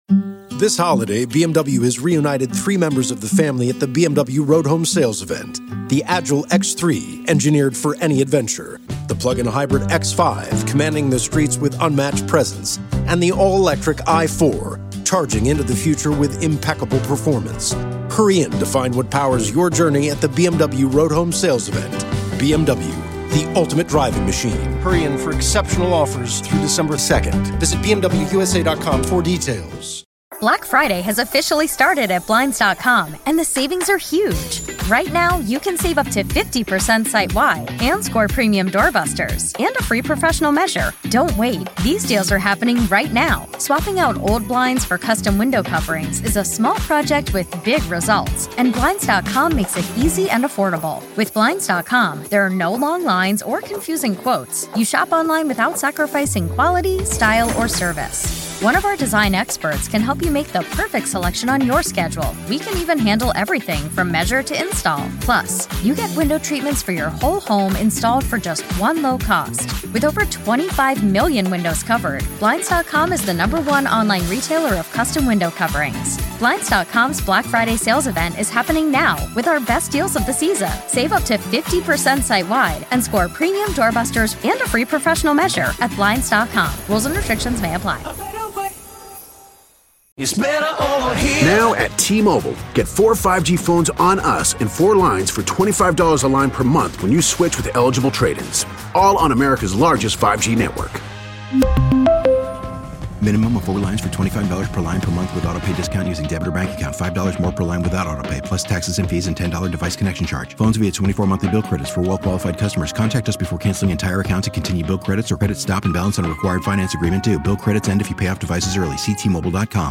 New Techno Mix